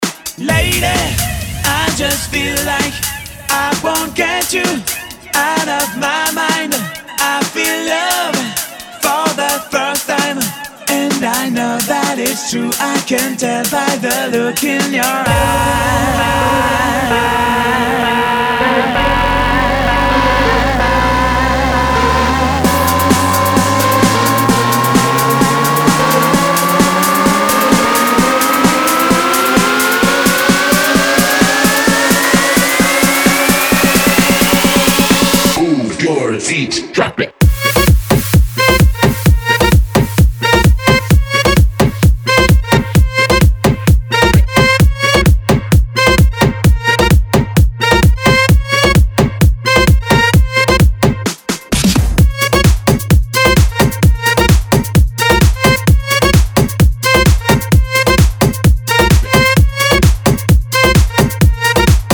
His style is unique and electrifying.